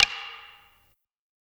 Perc [3].wav